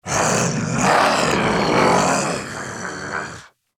Index of /server/sound/nmr_zomb_male
idle5.wav